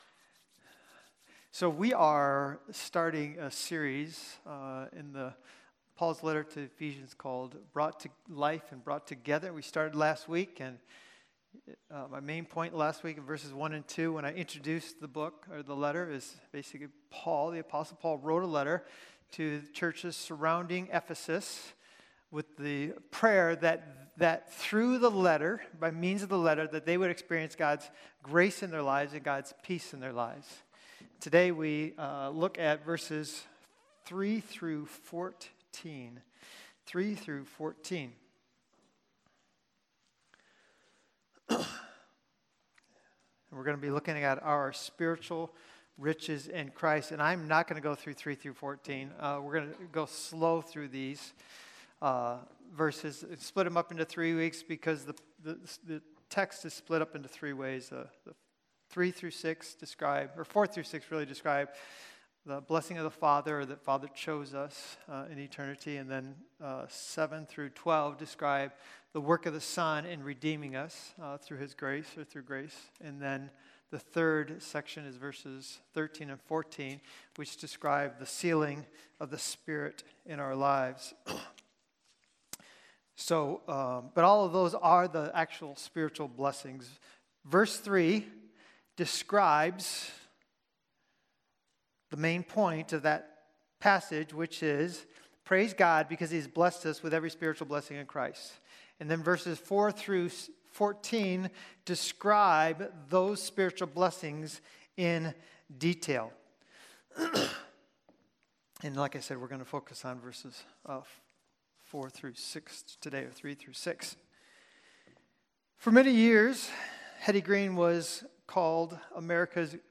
Sermons | Christian Community Church